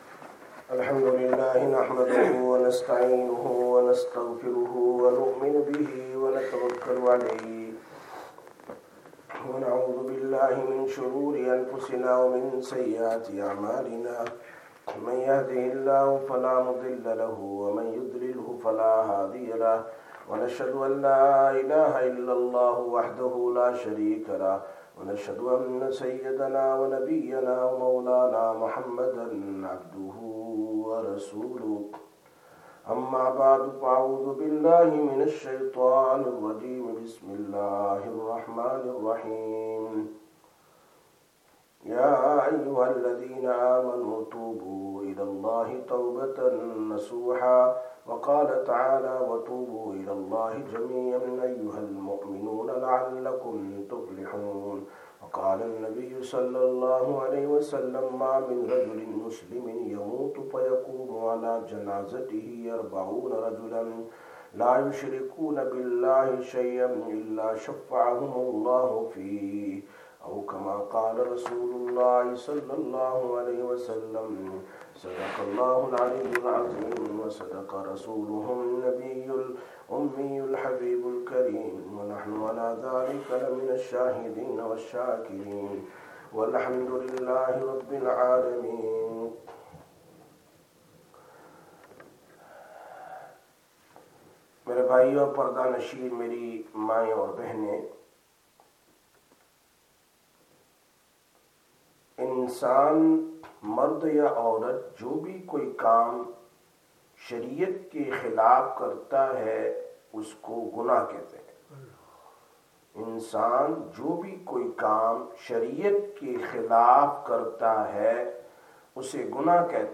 23/10/2024 Sisters Bayan, Masjid Quba